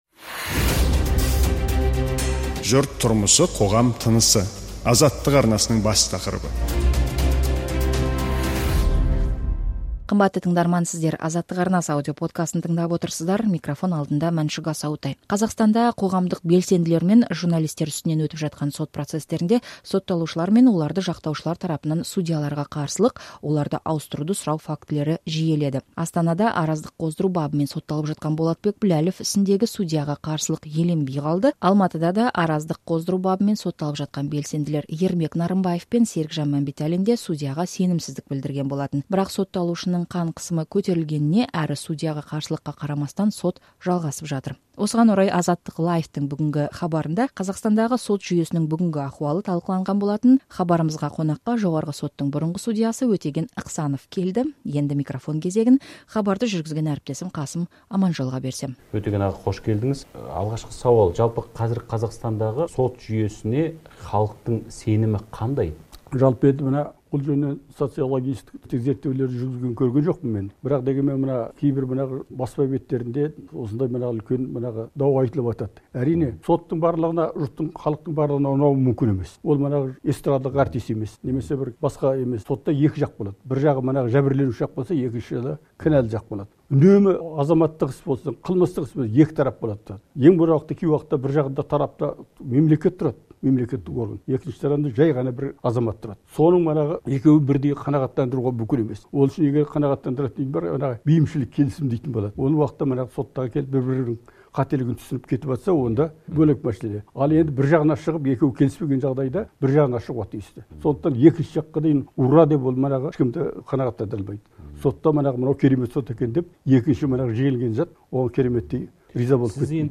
AzattyqLIVE хабарында Қазақстандағы сот жүйесінің ахуалы талқыланды. Эфир қонағы жоғарғы соттың бұрынғы судьясы Өтеген Ықсанов болды.